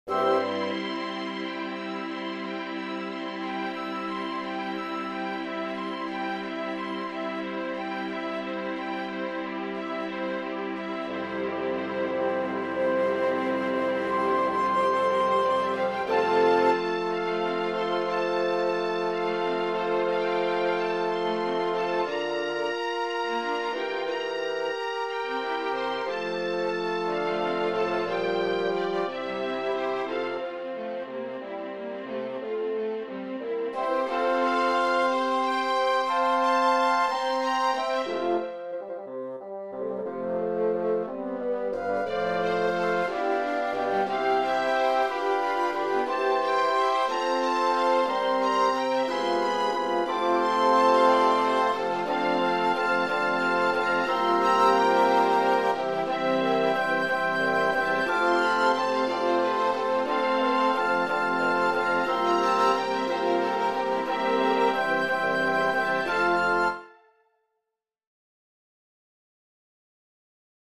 Arranging Symphonic, Piano, and Vocal Works for Performance on Carillon
Put staccati on pizzicati in bass.
Harmonic changes best in higher bells with smoothly ascending bass line.
Put them in higher octaves.
This drives the piece forward to a climax.